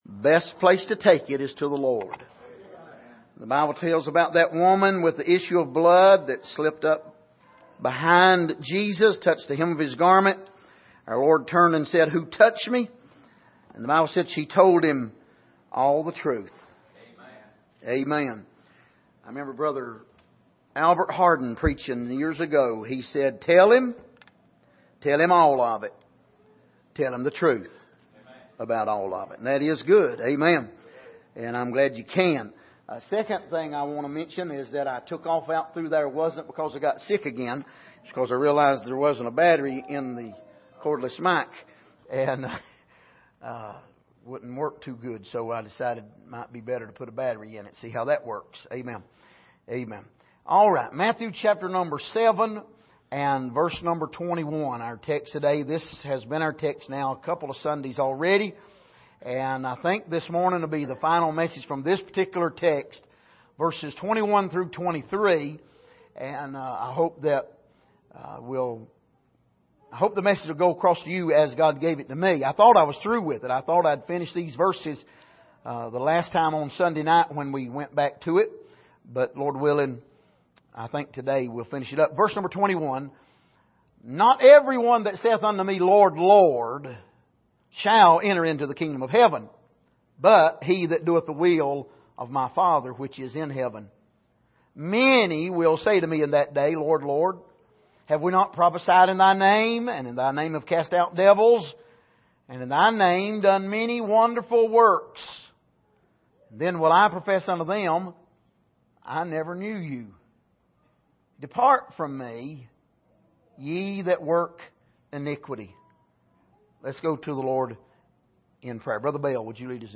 Passage: Matthew 7:21-23 Service: Sunday Morning